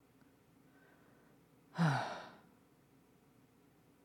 ため息をつく
音量注意！
これも『ささやき声・ひそひそ声を出す』とほぼ同じような状態になるのですが、こちらは息を多く吐くというのが地声系の筋肉の動きを抑制する要素になっています。